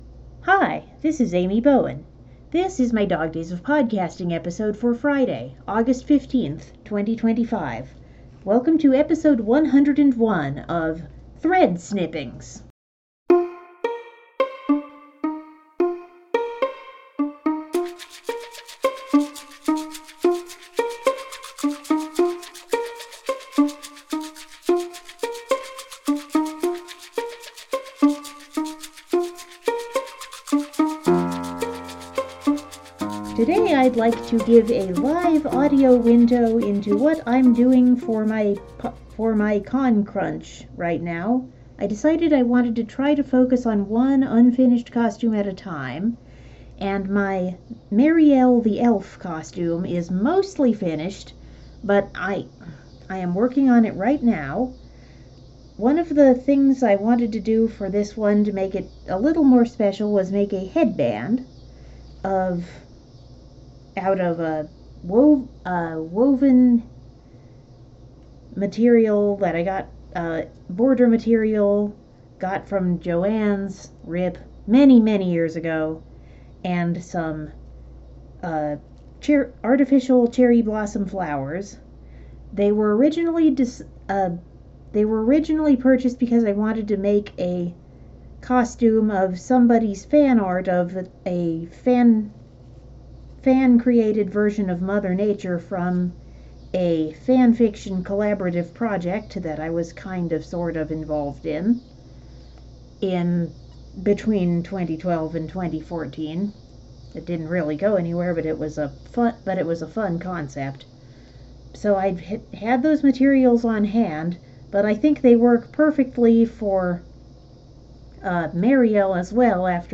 An off-the-cuff live recording made while hand-sewing Velcro onto a headband.